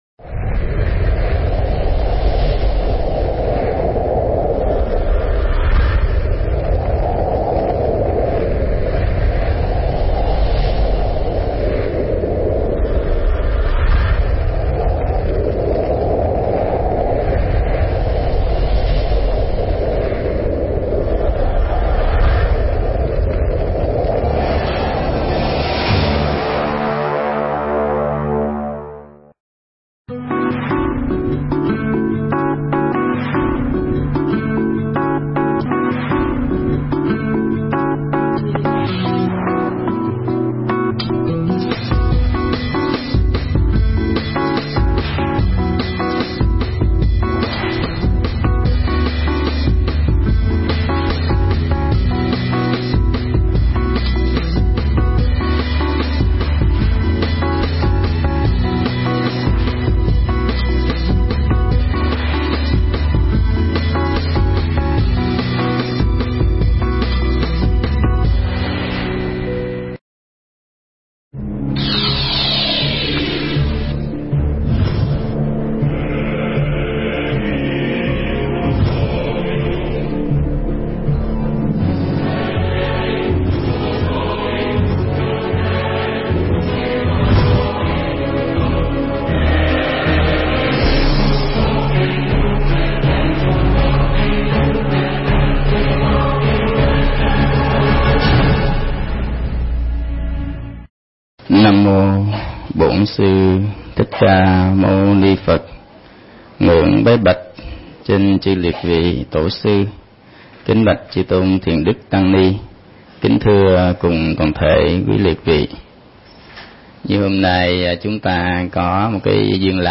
Mp3 Thuyết Giảng Sức mạnh của tâm
thuyết tại nhà hàng chay Mandala, Sương Nguyệt Ánh, Quận 1